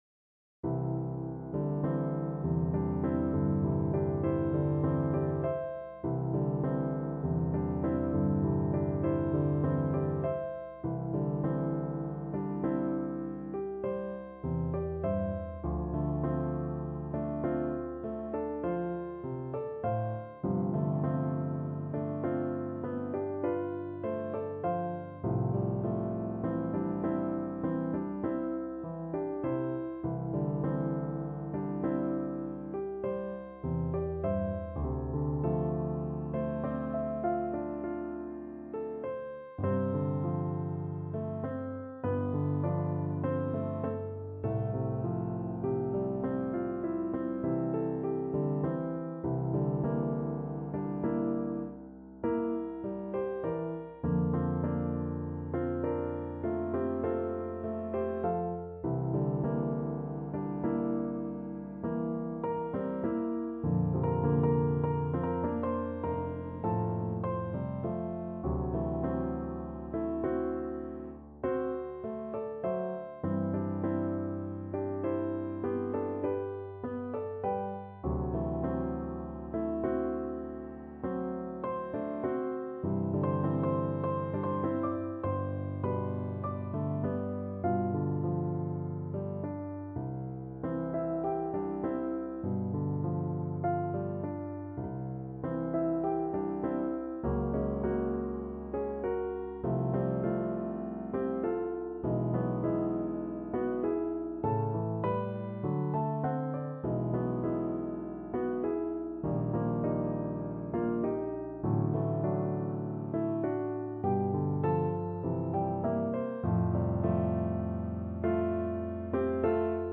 C major (Sounding Pitch) G major (French Horn in F) (View more C major Music for French Horn )
4/4 (View more 4/4 Music)
Lento =50
Classical (View more Classical French Horn Music)